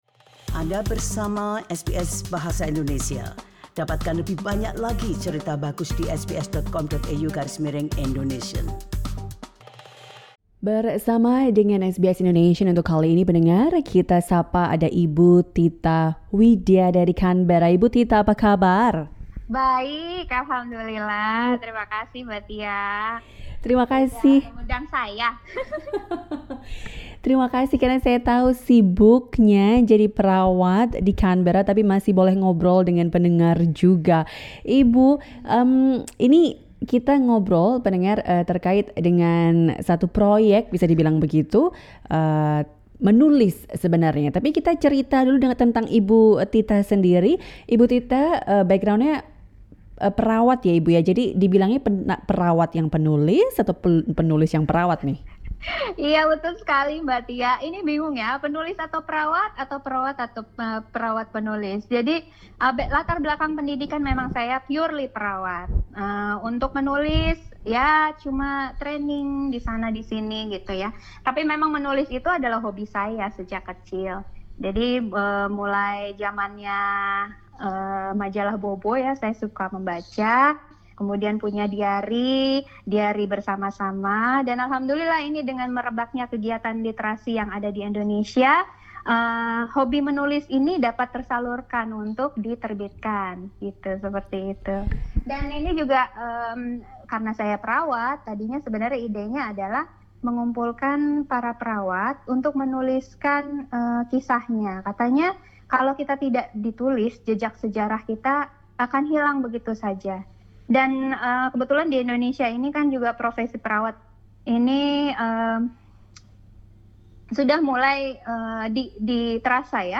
Dengarkan wawancara selengkapnya di podcast SBS Indonesian.